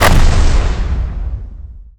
poly_explosion_nuke4.wav